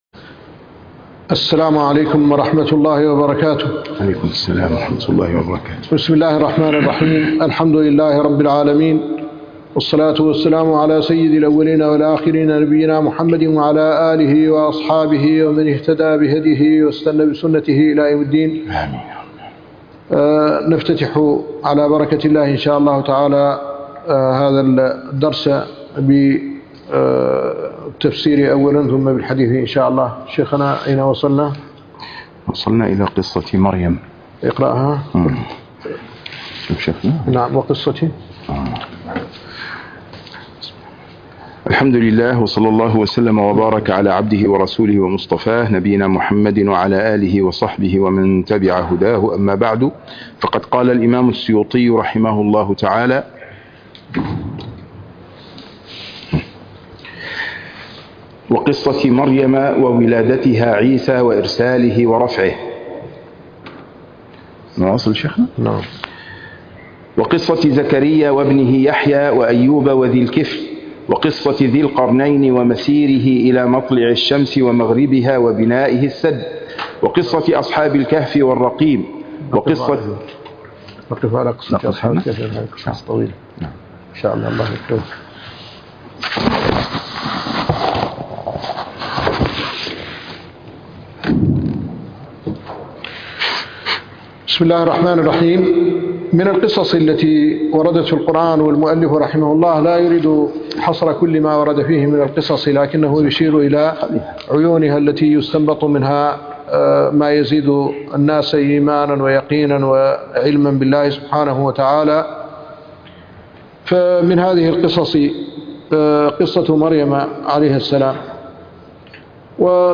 الدرس العاشر من شرح وتعليق الشيخ الددو على كتاب الإكليل في استنباط التنزيل للإمام جلال الدين السيوطي - الشيخ محمد الحسن ولد الددو الشنقيطي